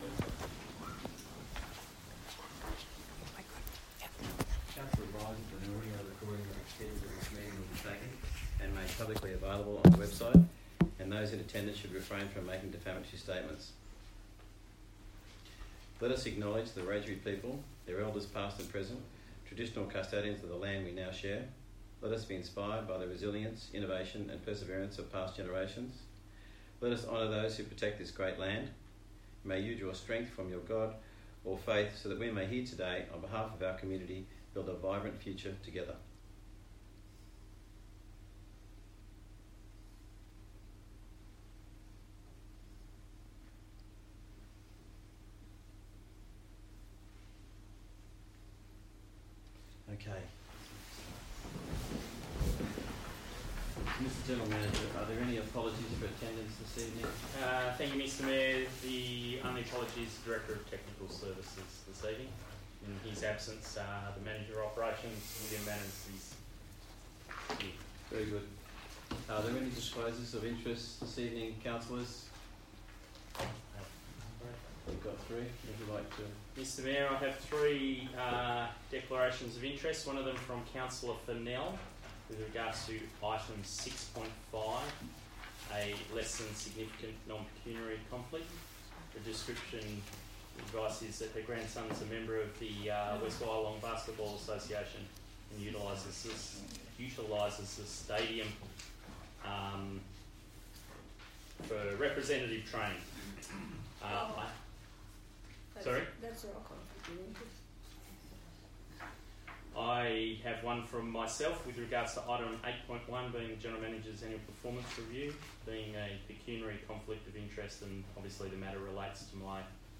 13 August 2024 Ordinary Meeting